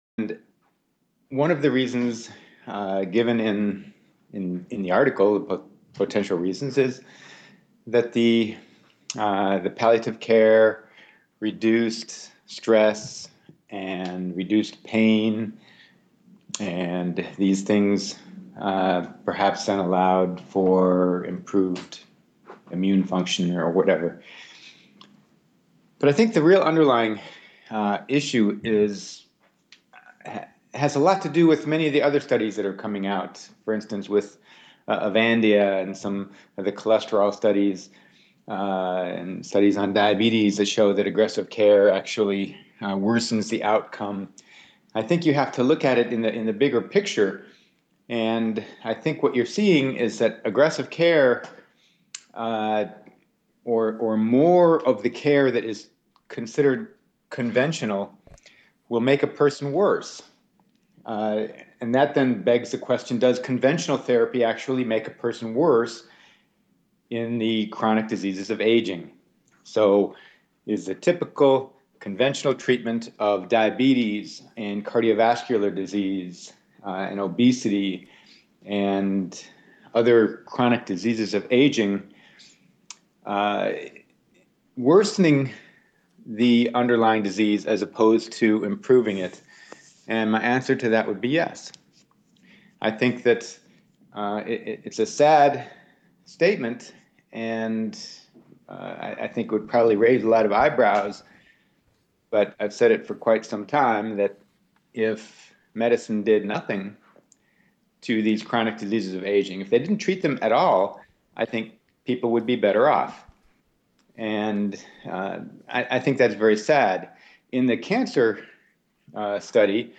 Extended Version of the Interview